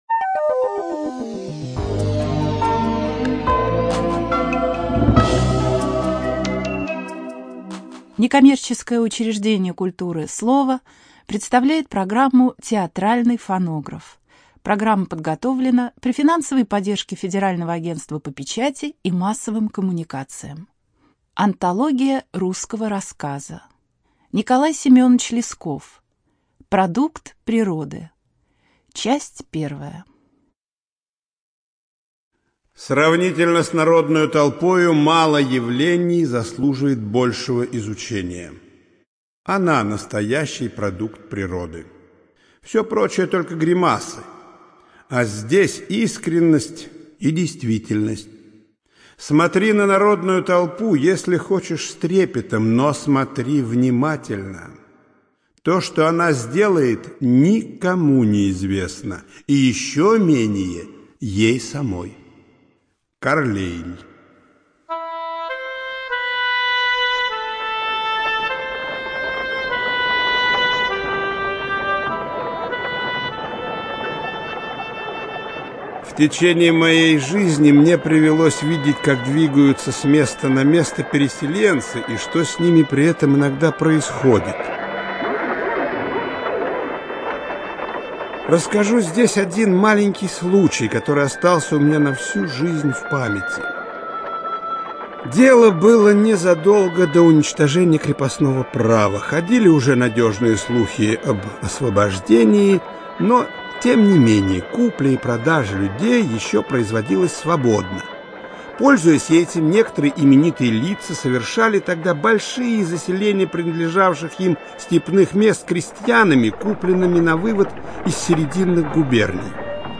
ЖанрРадиоспектакли